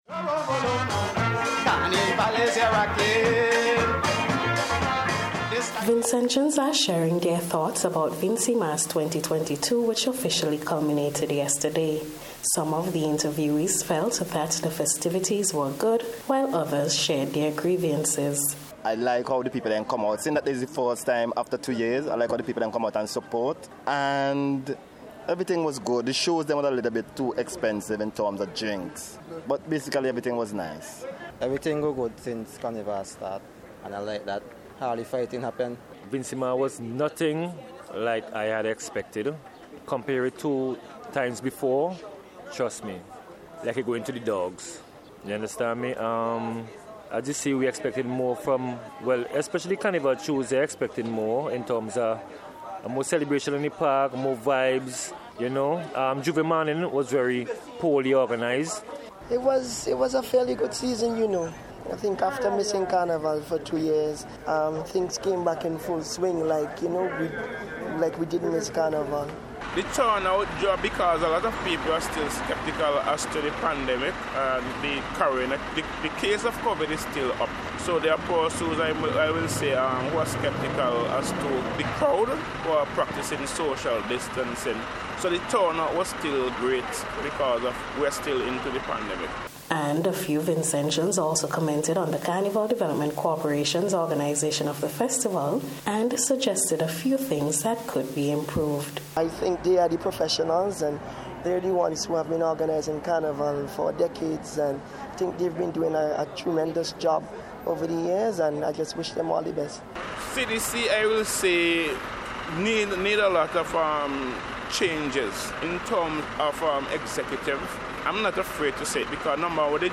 NBC News took to the streets this morning to hear people’s perception on this Year’s festivities.